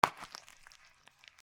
7 刺す
/ H｜バトル・武器・破壊 / H-15 ｜剣・刀1(生音寄り)